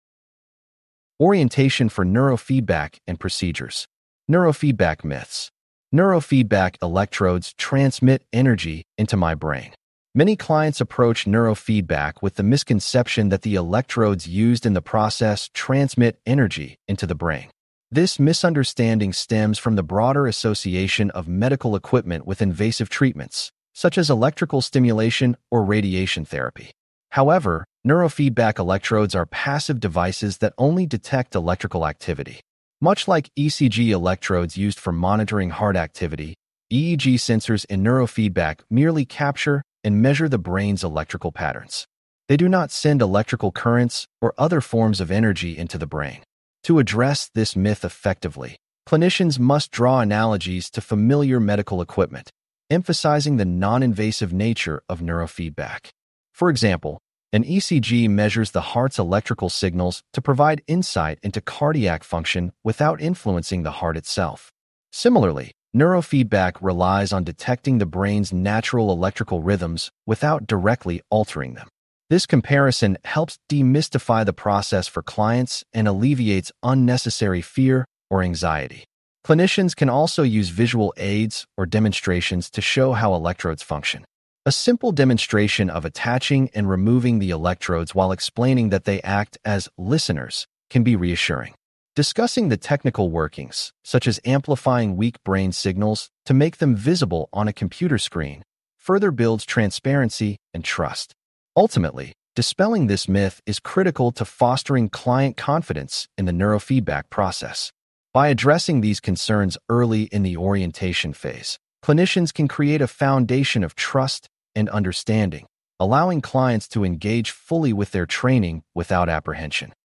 We will cover client preparation in four sections: Orientation for Neurofeedback, Client Preparation with Relaxation Training, Client Preparation with Respiration Training, and Client Preparation with Heart Rate Variability Biofeedback. Please click on the podcast icon below to hear a full-length lecture.